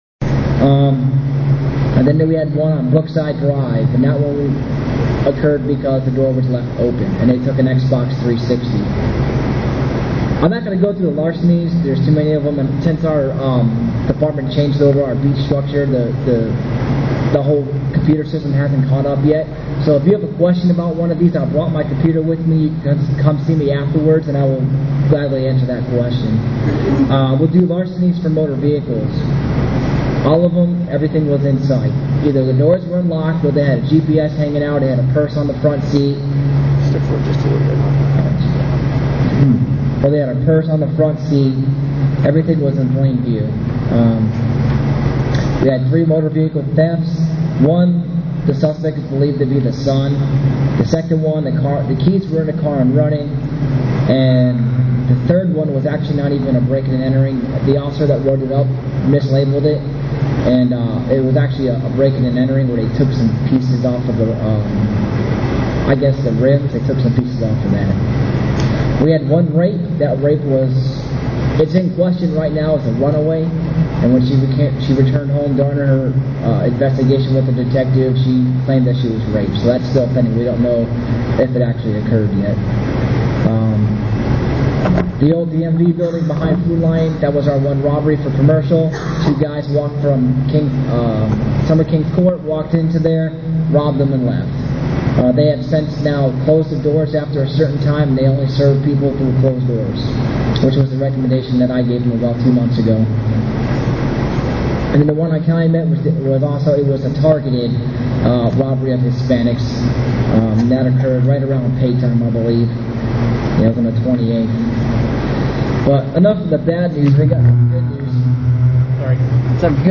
Media from East CAC April 2010 meeting
Audio and video from the April 2010 meeting of the East CAC is now available.
(Once again, I apologize for the poor audio quality).